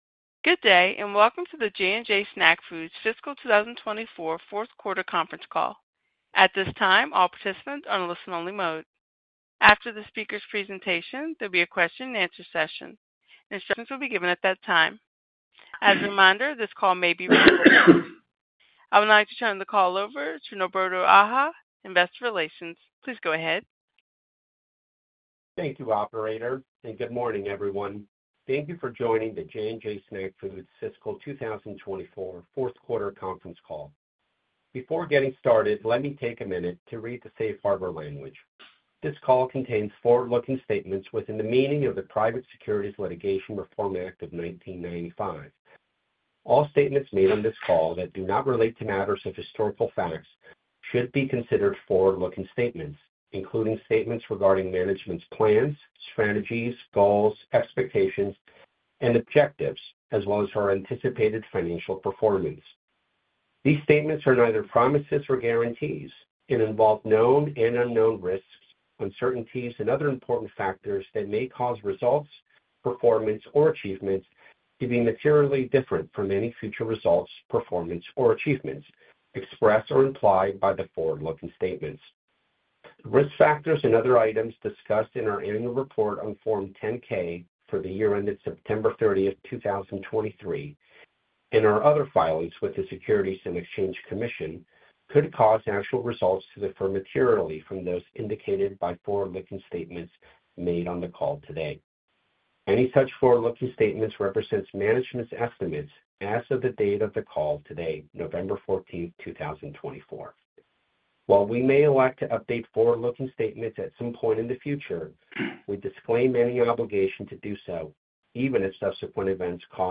JJ-Snack-Foods-Fourth-Quarter-2024-Conference-Call.mp3